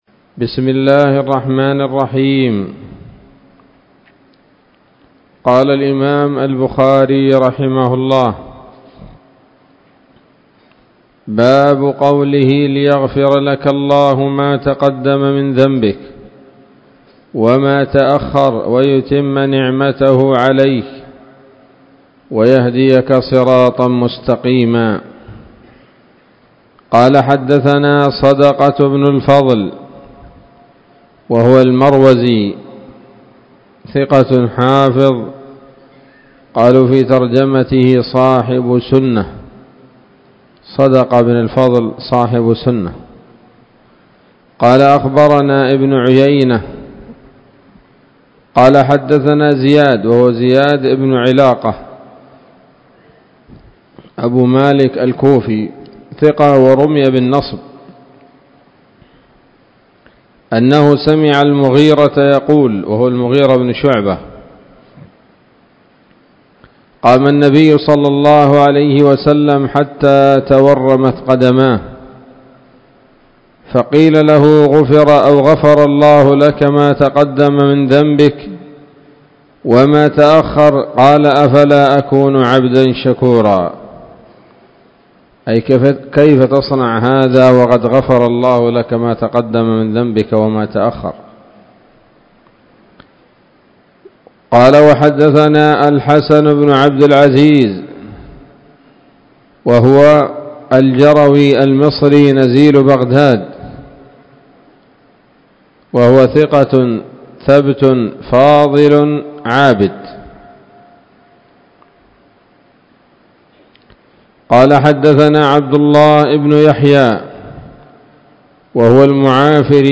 الدرس الثاني والثلاثون بعد المائتين من كتاب التفسير من صحيح الإمام البخاري